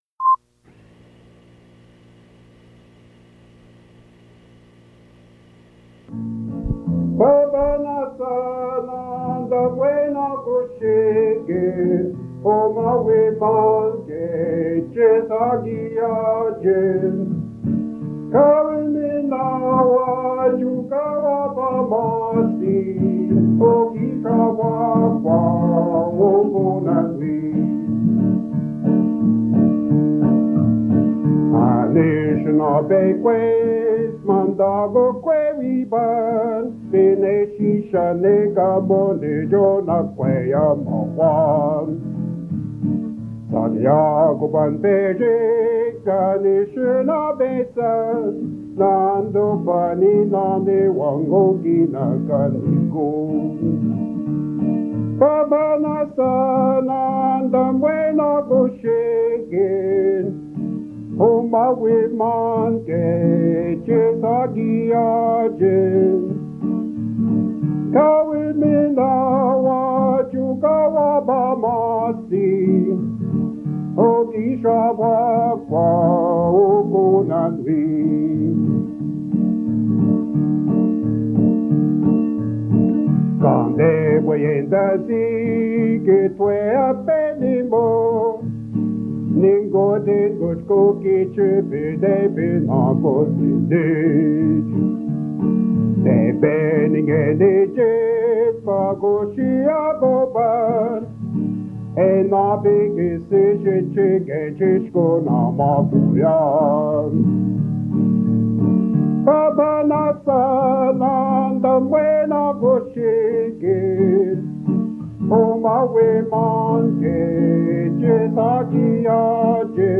Country music